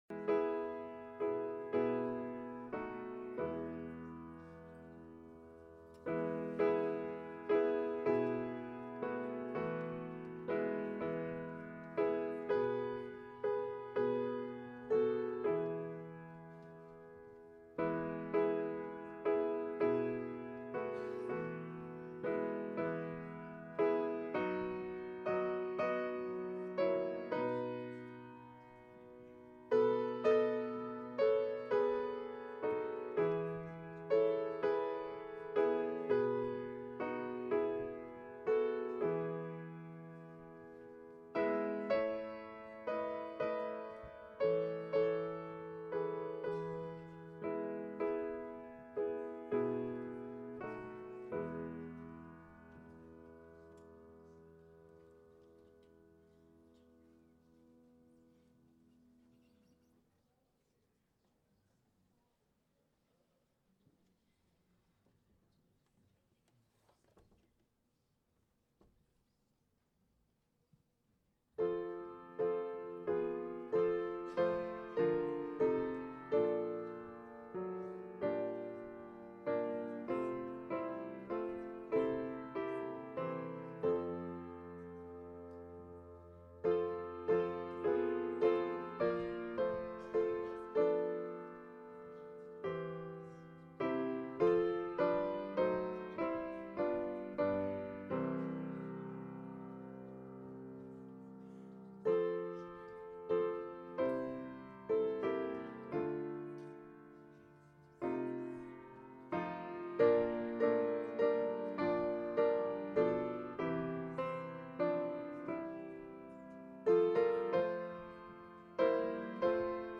Wednesday Passion Week Service